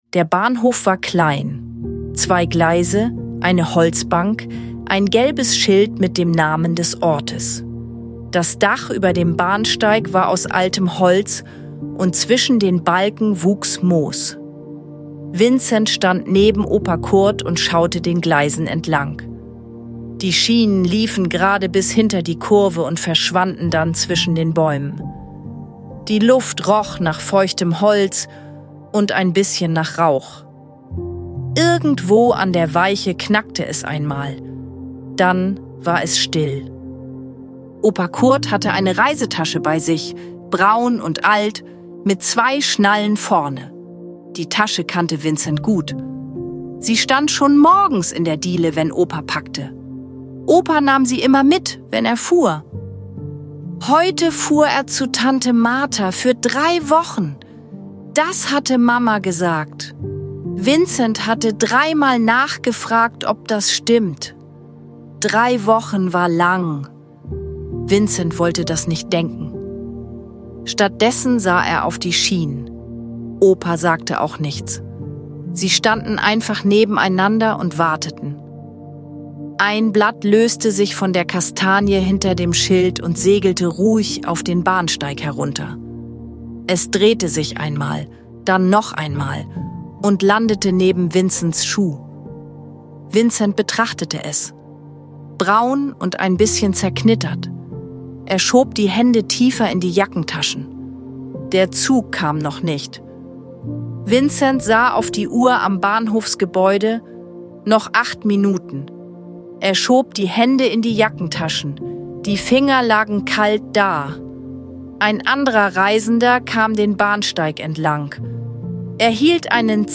In dieser ruhigen Geschichte geht es um Nähe, Erinnerungen und das Gefühl, dass etwas bleibt – auch wenn jemand geht. Sanfte Bilder und eine warme Atmosphäre begleiten durch diesen besonderen Moment. Eine Geschichte zum Ankommen, Loslassen und Einschlafen.